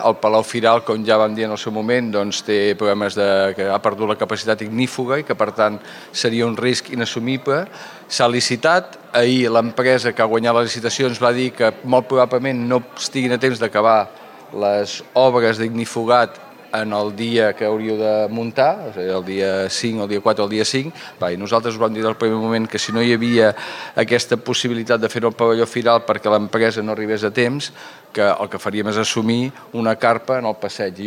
L’alcalde de la Bisbal d’Empordà, Òscar Aparicio, demana disculpes per la situació i avança que, en cas de no poder celebrar la Indilletres al pavelló, es farà en una carpa al passeig Marimon Asprer.